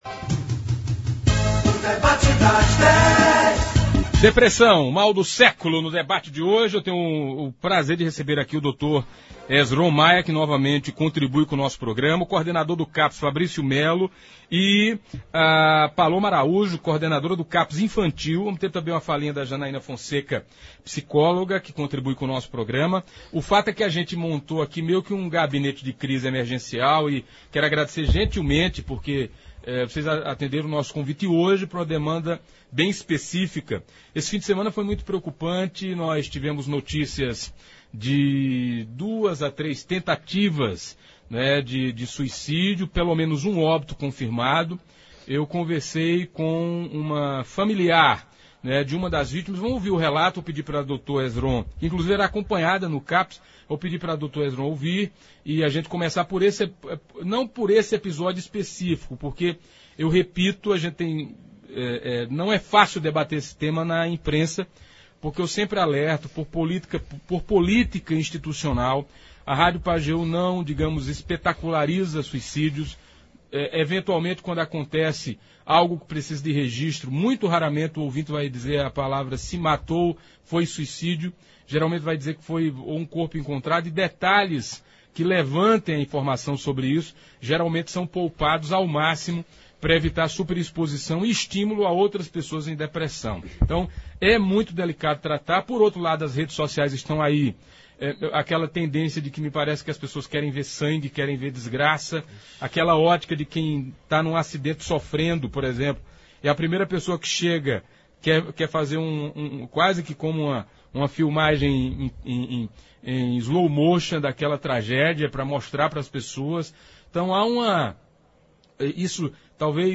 Eles falaram sobre a depressão, considerada o “mal do século”, seus perigos, como identificar, a importância do apoio familiar dentre outras coisas. Ouça abaixo a íntegra do debate de hoje: